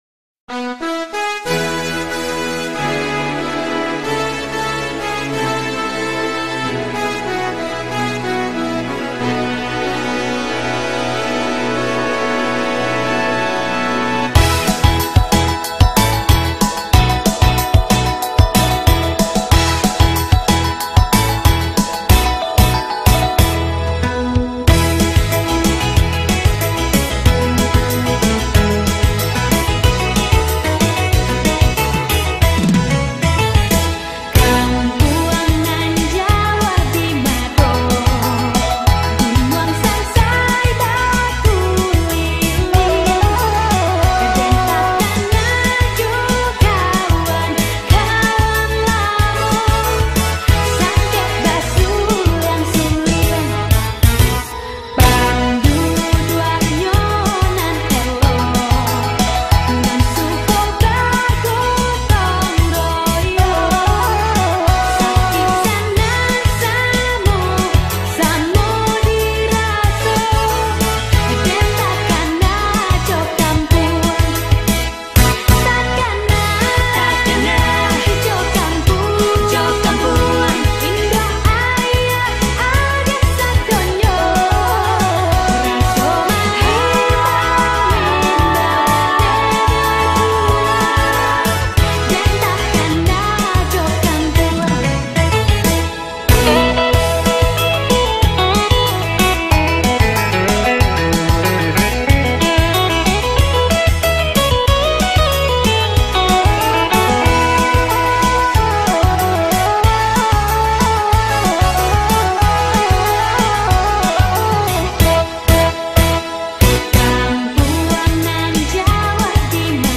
Lagu Daerah Sumatera Barat